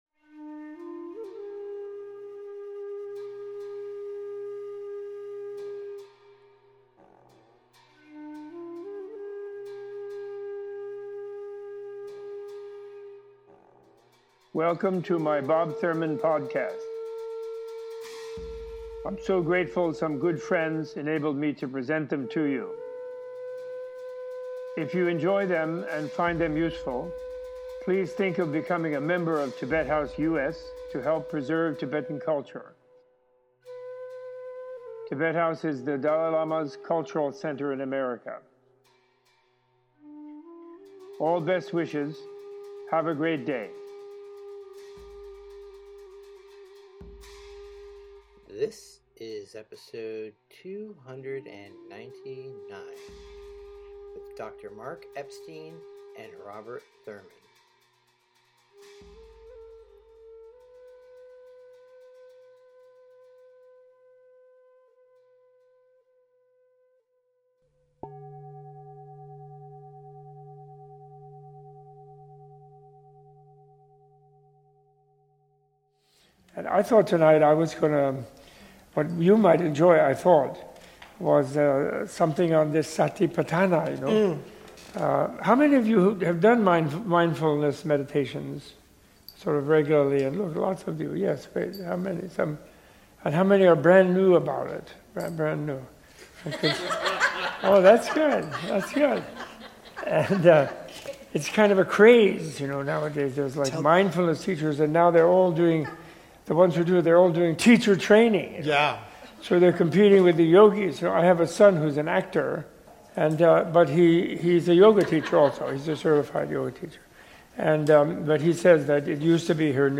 Joined by Dr. Mark Epstein, Thurman discusses the Mahāsatipatthāna Sutta’s connection to the Heart Sutra, the four focuses of mindfulness, the legacy of Wilhelm Reich, the practice of circumambulation of stupas and sacred sites, and the concept of the “good enough mother” as presented by D.W. Winnicott.